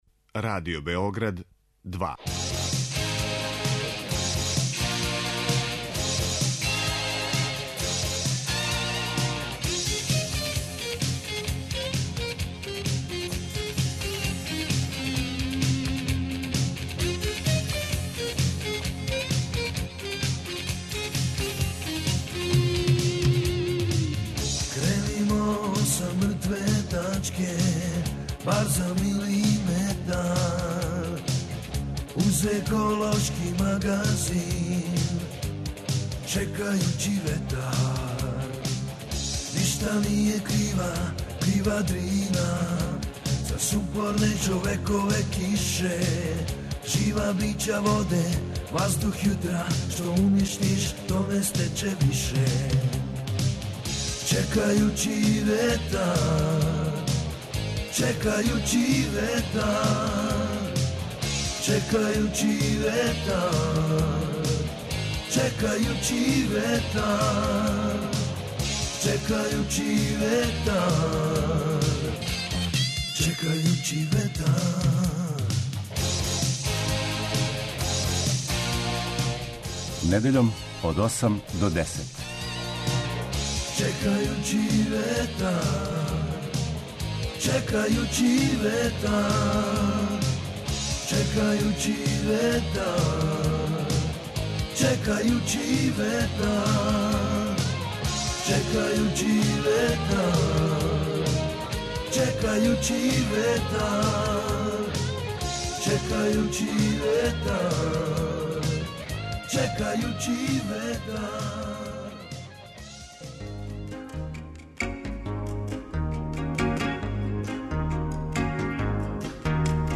Еколошки магазин